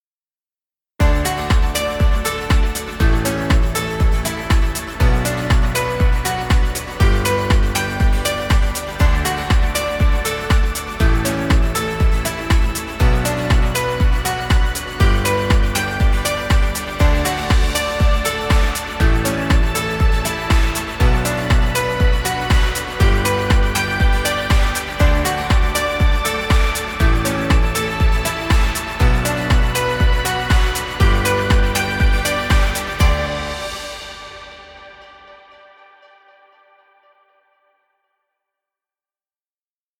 Happy motivational music. Background music Royalty Free.